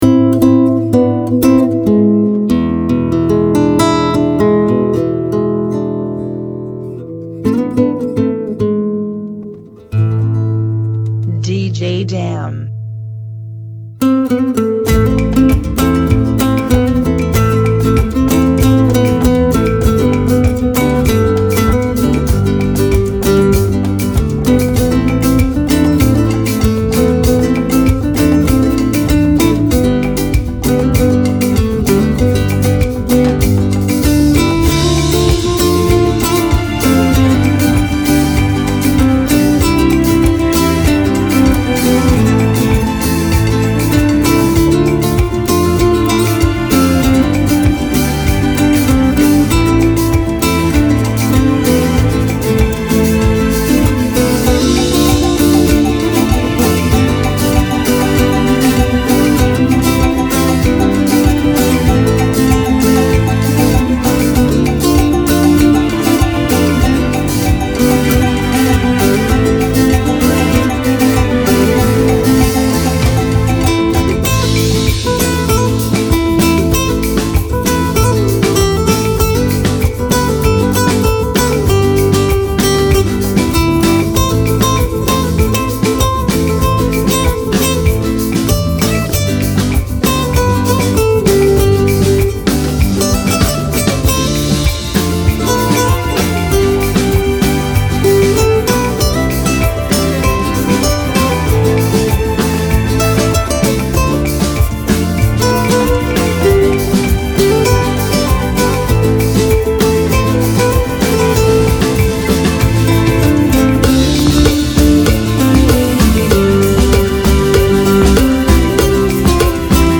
97 BPM
Genre: Salsa Remix